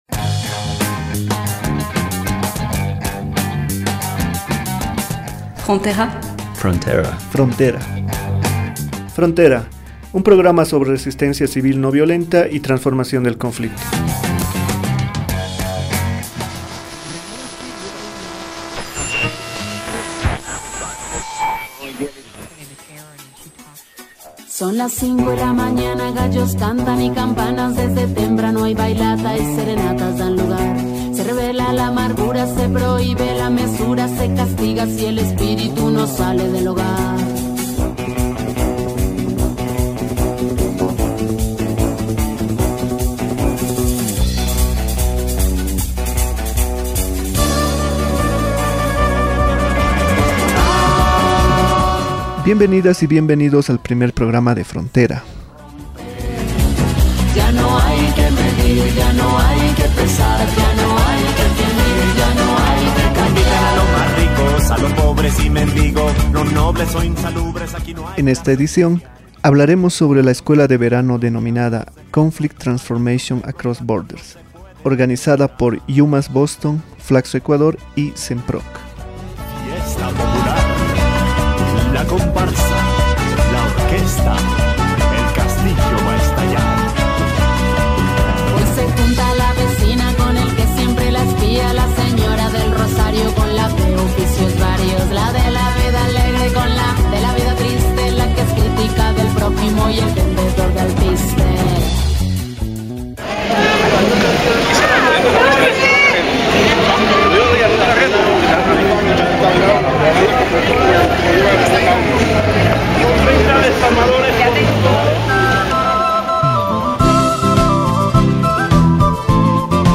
En esta memoria radiofónica escucharás los testimonios de docentes y estudiantes quienes no solo hablarán sobre el contenido del programa, sino sobre cómo llevarán lo aprendido en estas jornadas a su vida profesional y personal.